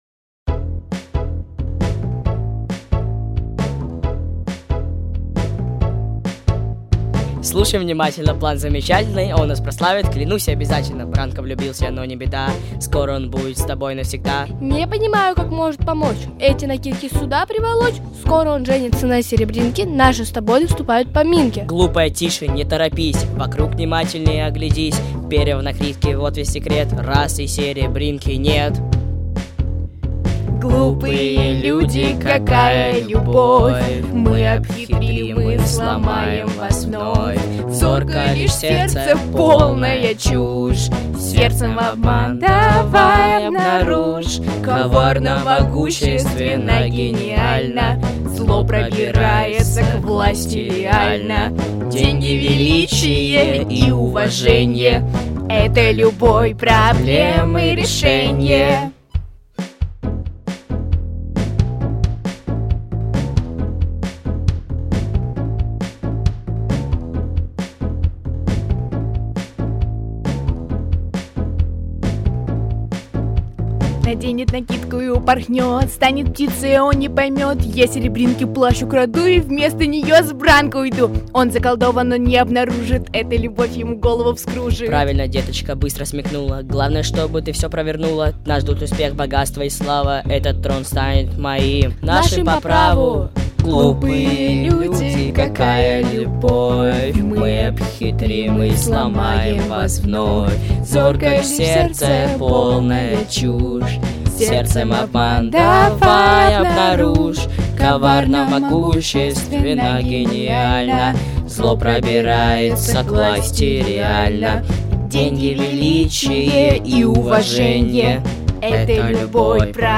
Записано в студии Easy Rider в ноябре–декабре 2025 года